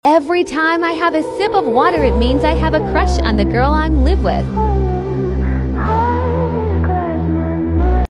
Shhhhh sound effects free download